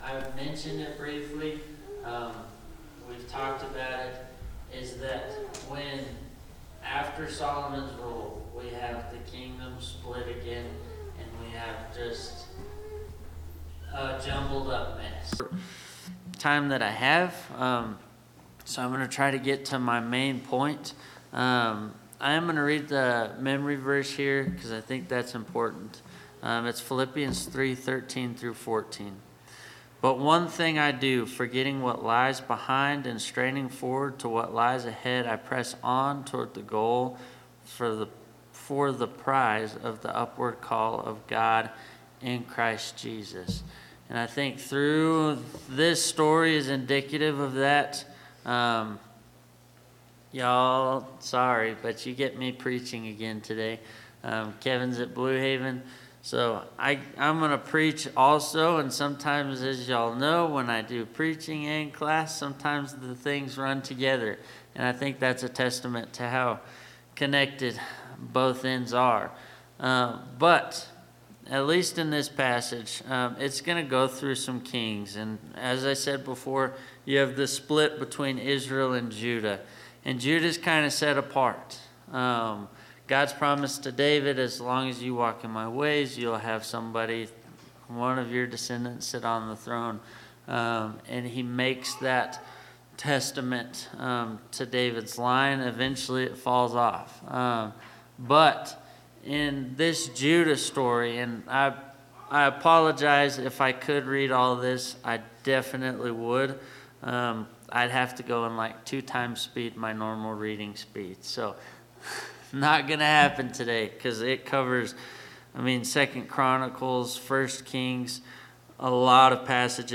Bible Class 06/08/2025 - Bayfield church of Christ
Sunday AM Bible Class